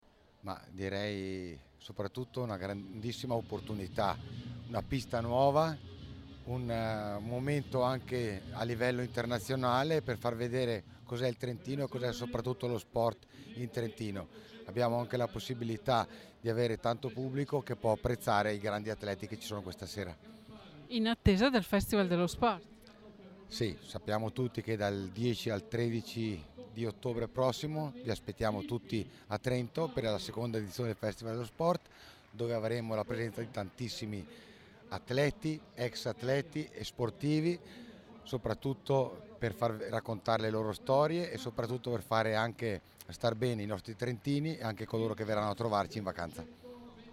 FAILONI_inagurazione_pista_ROVERETO_palio_Quercia.mp3